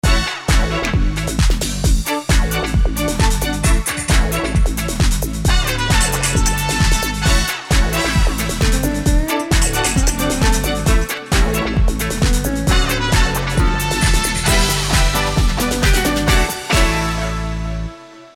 Ищу духовые отсюда
Может быть кто-то подскажет, откуда взяты сэмплы духовых?